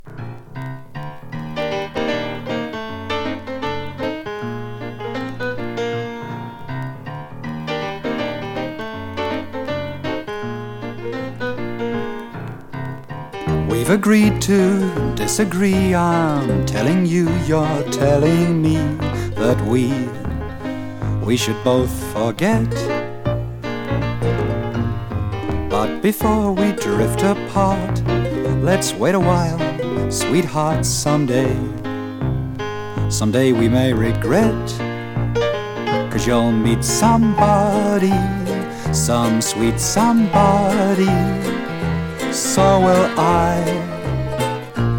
Jazz, Pop, Ragtime, Big Band　USA　12inchレコード　33rpm　Stereo